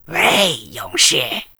文件 文件历史 文件用途 全域文件用途 Gbn_amb_01.ogg （Ogg Vorbis声音文件，长度1.6秒，120 kbps，文件大小：24 KB） 源地址:游戏语音 文件历史 点击某个日期/时间查看对应时刻的文件。